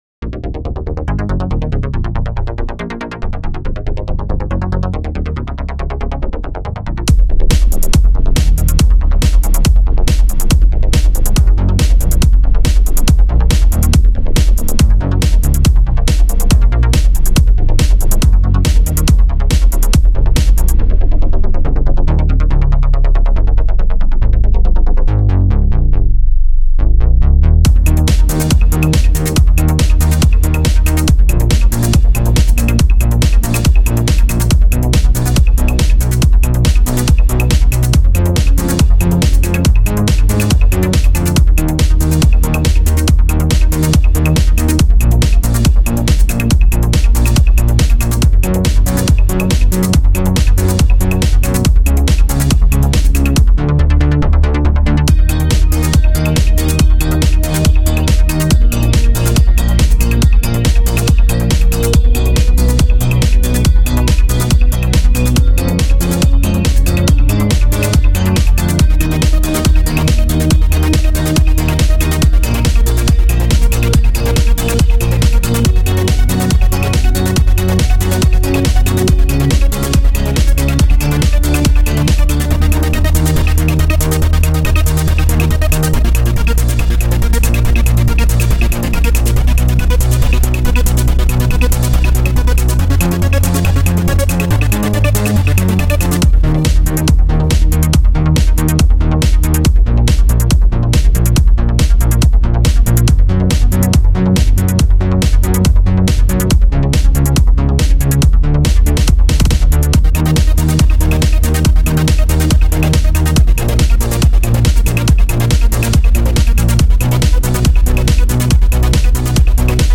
Music / Techno
Great work, this tune is like a high speed chase.
~the 80's made an Angry come back~